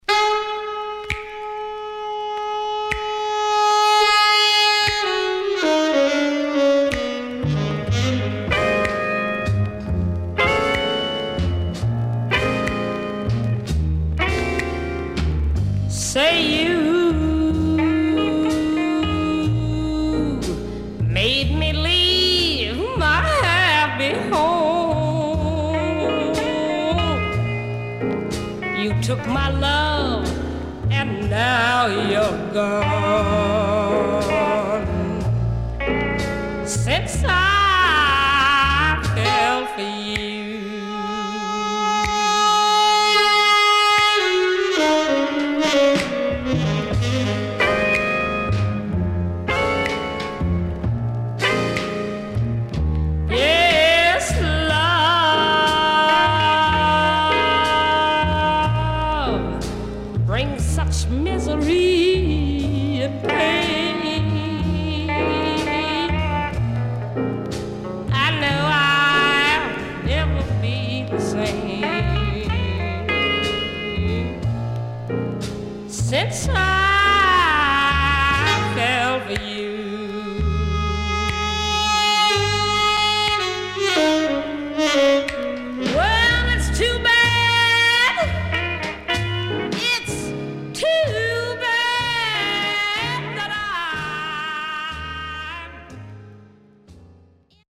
61年艶のある歌唱が素晴らしいSwing Number.5475
SIDE A:少しノイズ入ります。